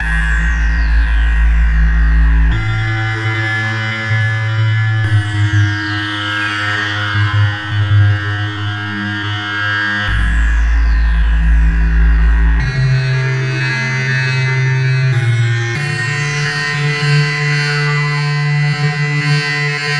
Tamboura无人机
描述：一个漂亮的多汁的Tamboura/Tanpura无人机的印度音色。
标签： 环境 无人驾驶 实验 印度 器乐 西塔琴 坦布拉琴 坦布拉琴
声道立体声